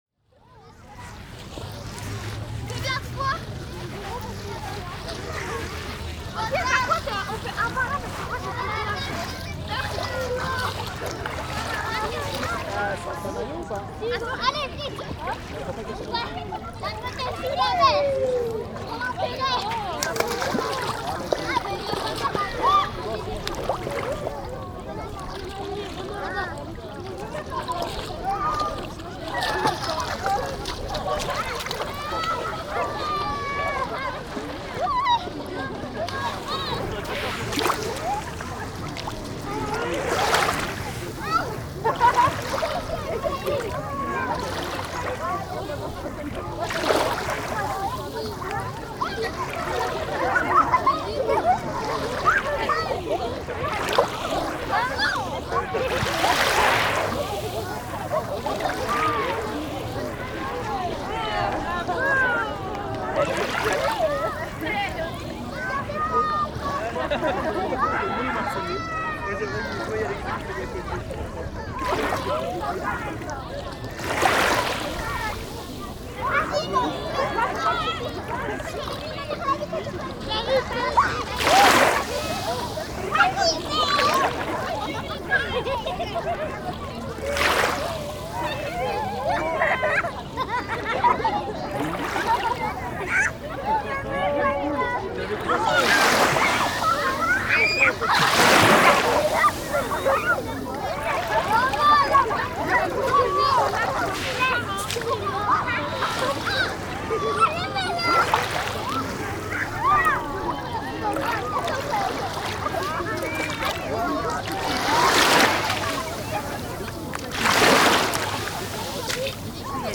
Jeux de plage Plage de Bonneveine | Marseille, France Rires d’enfants, éclats de joie et jeux dans les vagues.Children’s laughter, bursts of joy and play in the waves.Prise de son : OR…